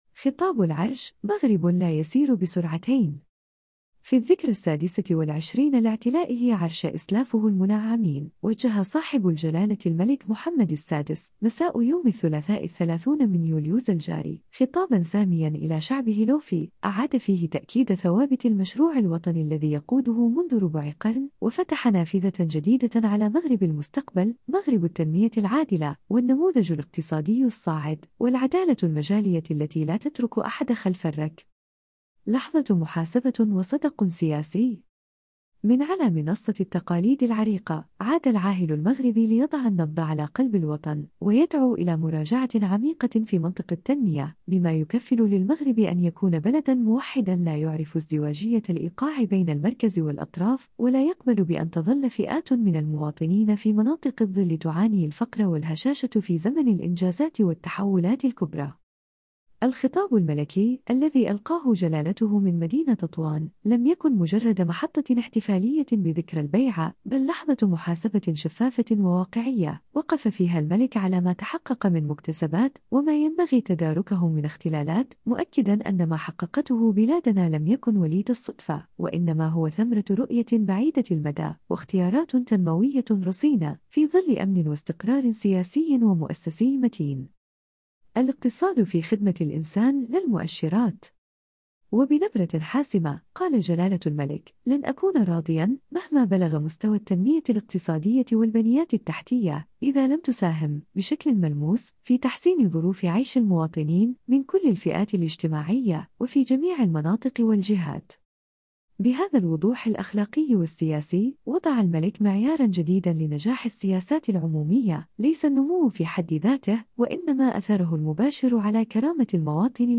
خطاب العرش
وبنبرة حاسمة، قال جلالة الملك: “لن أكون راضيا، مهما بلغ مستوى التنمية الاقتصادية والبنيات التحتية، إذا لم تساهم، بشكل ملموس، في تحسين ظروف عيش المواطنين، من كل الفئات الاجتماعية، وفي جميع المناطق والجهات”.